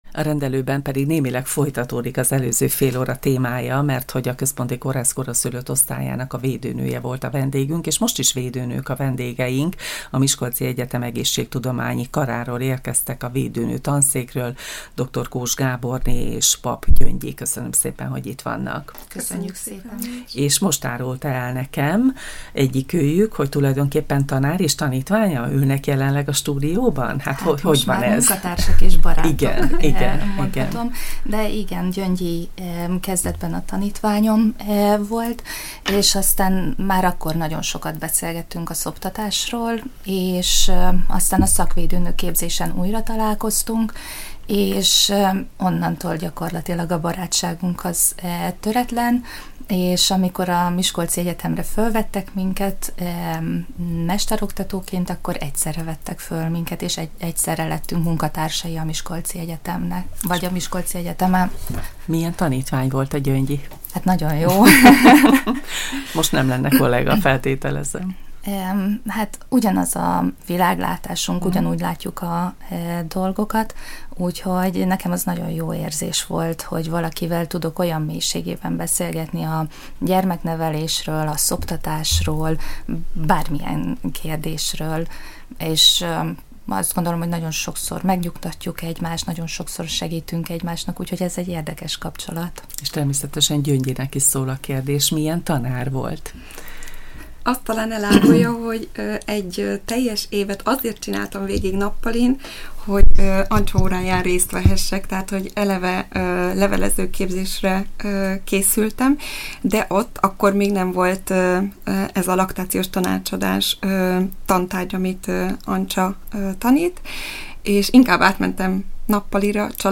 Saját, anyai tapasztalatuk okán is beszélgettünk a hivatás szépségeiről, hiszen kettejüknek összesen kilenc gyermekük van.